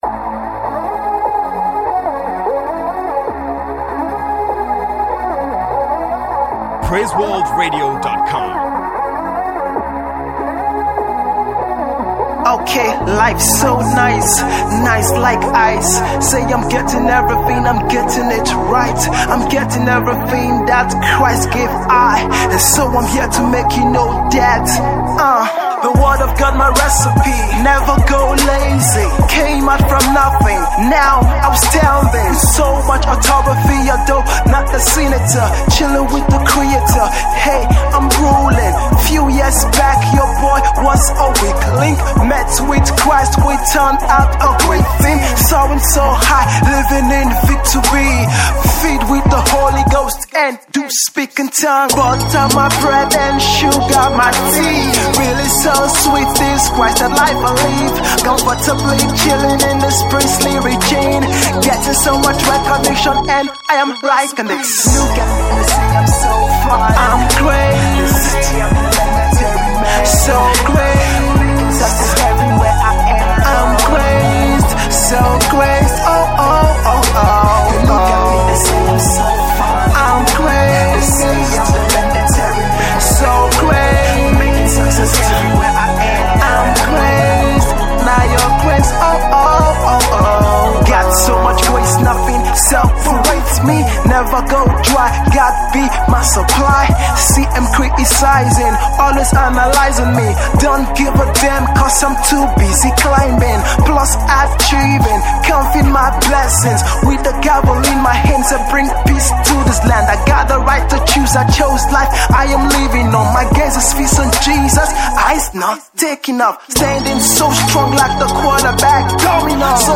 is a Nigerian born gospel hip hop artiste.